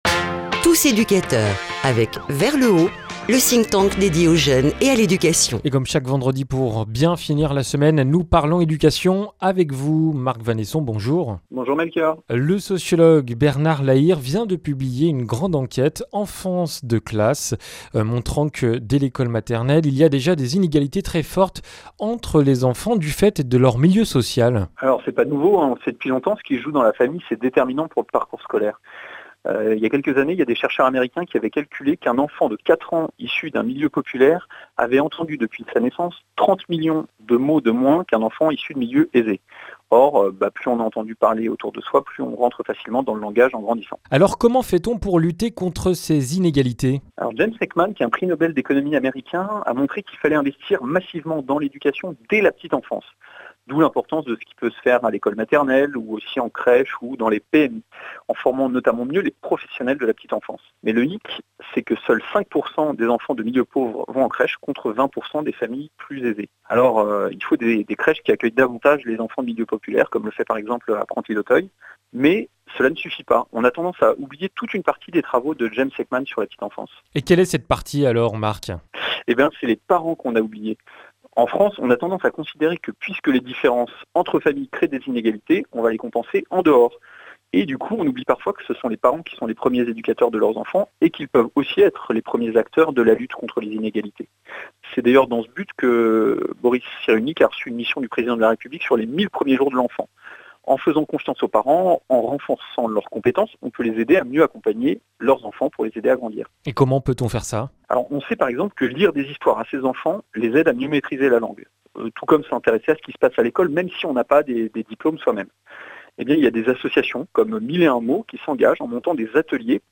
qui présente au micro de RCF des initiatives et des idées innovantes dans le domaine de la jeunesse et de l’éducation.